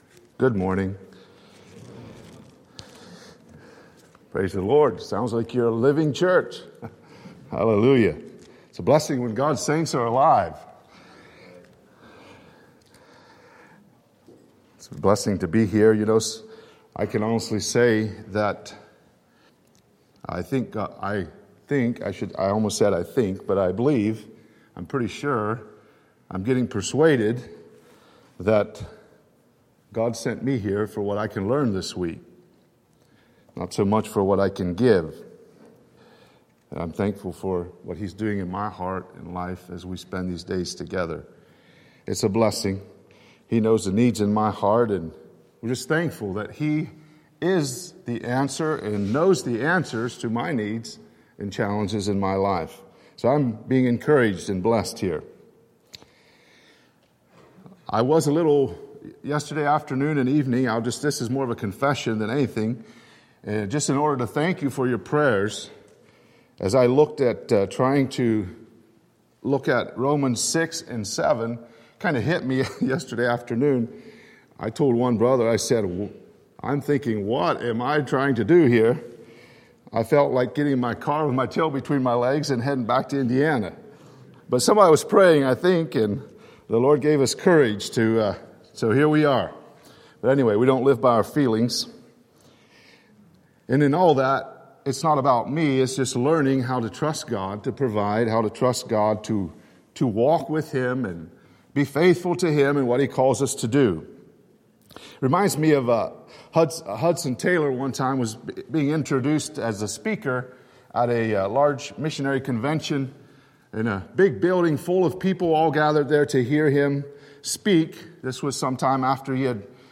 Living Hope | Sermon